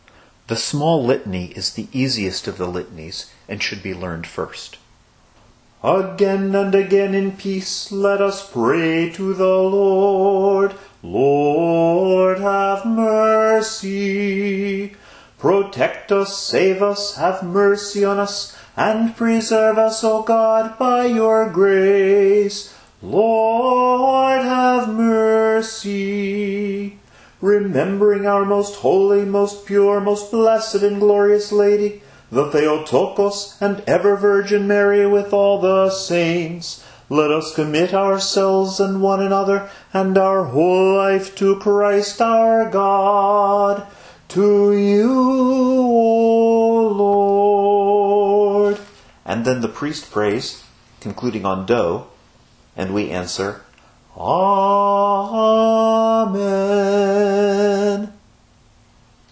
The people respond with a melody that starts on sol, a perfect fourth below the deacon's ending note:
The people's singing is scale-wise, with only two leaps: down a perfect fourth at the beginning of the first "Lord, have mercy", and up a major third in the "To you, O Lord."